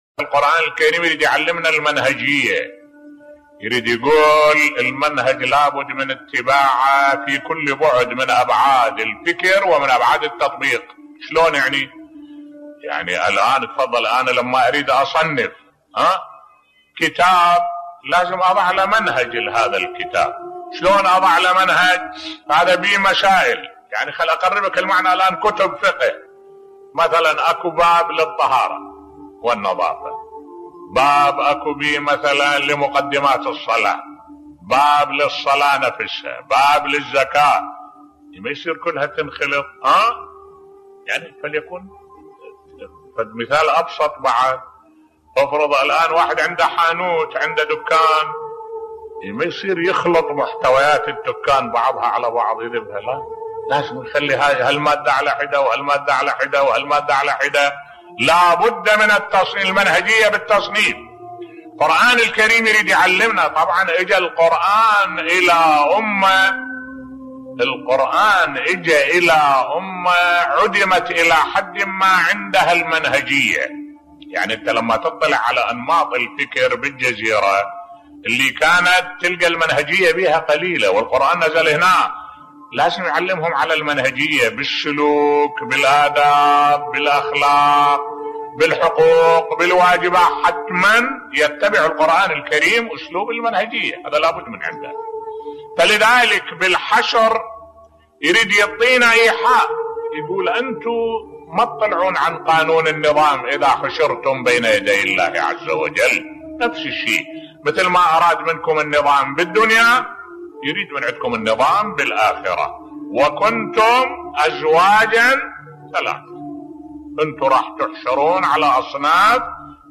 ملف صوتی ضرورة المنهجية في الفكر و التفكير بصوت الشيخ الدكتور أحمد الوائلي